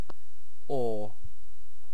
Mid ɛː ɜː ɔː
En-uk-awe.ogg.mp3